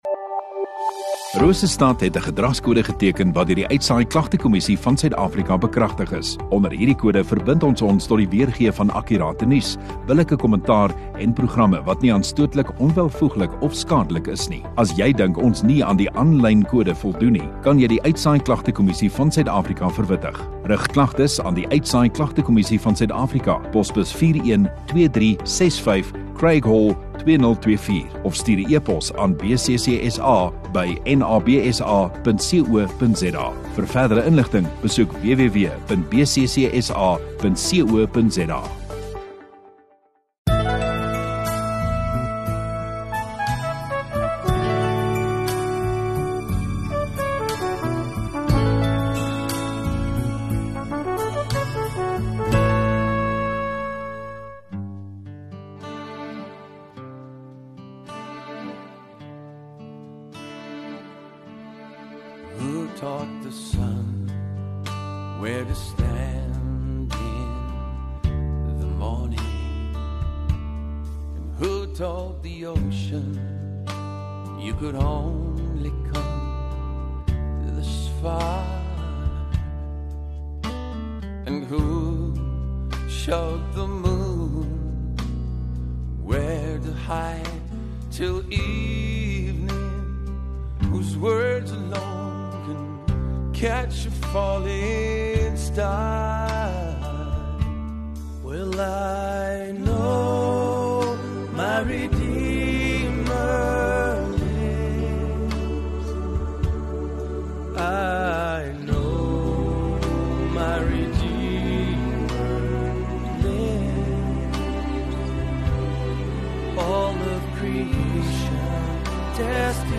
7 Jul Sondagaand Etediens